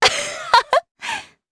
Hilda-Vox_Happy3_jp.wav